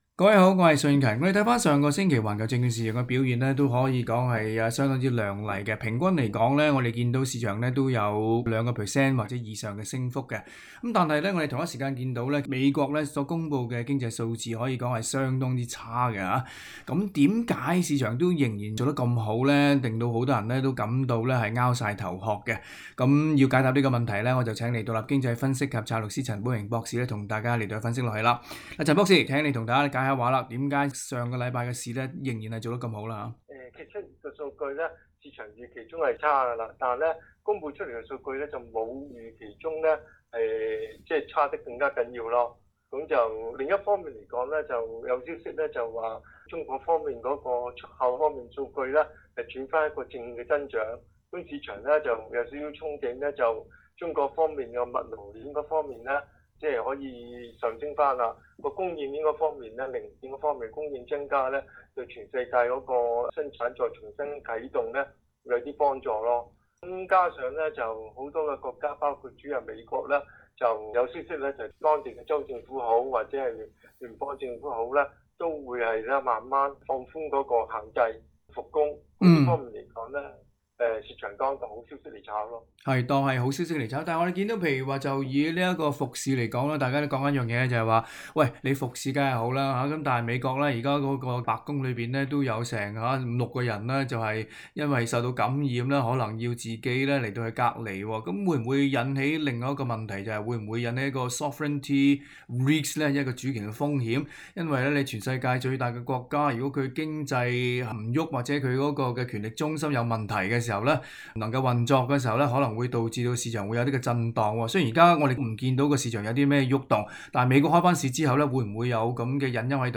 詳情收錄在今天的訪問內容。